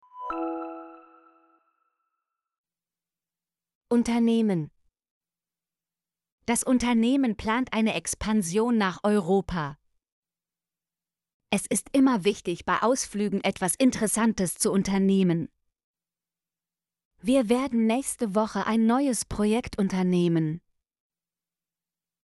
unternehmen - Example Sentences & Pronunciation, German Frequency List